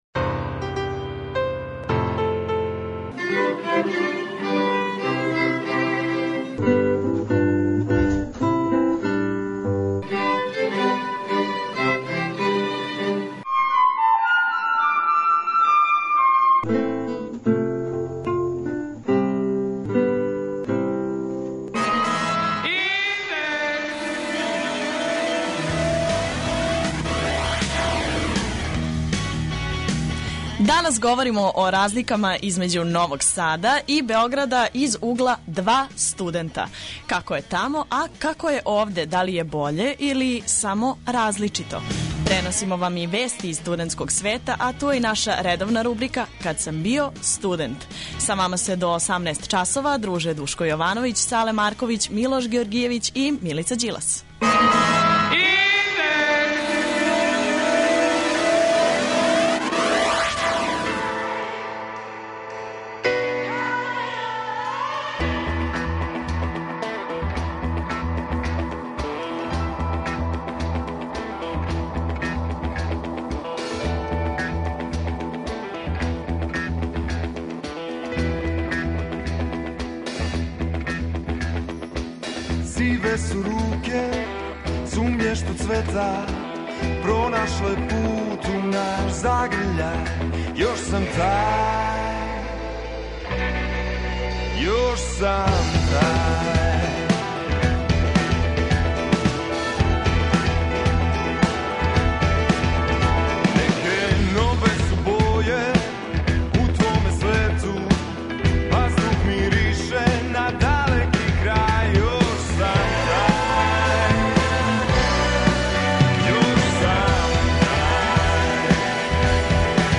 Разлога је више, а како саговорници кажу разлике између Новог Сада и Београда су значајне.
преузми : 20.96 MB Индекс Autor: Београд 202 ''Индекс'' је динамична студентска емисија коју реализују најмлађи новинари Двестадвојке.